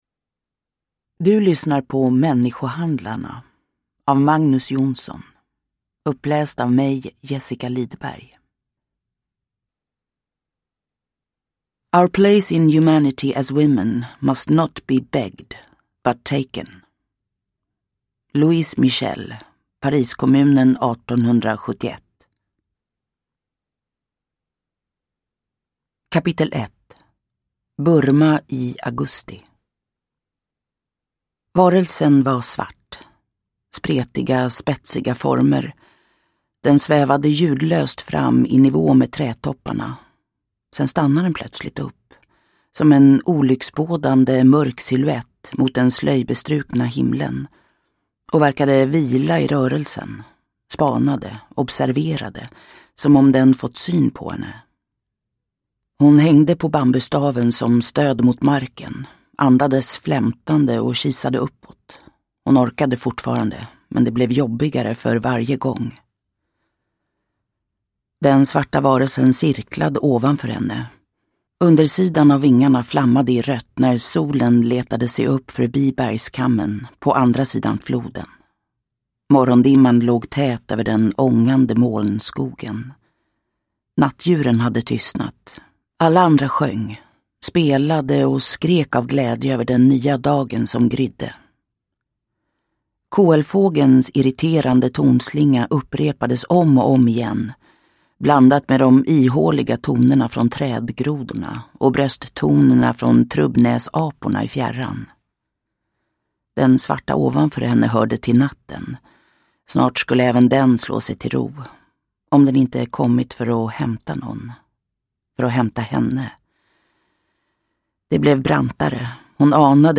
Människohandlarna – Ljudbok
Uppläsare: Jessica Liedberg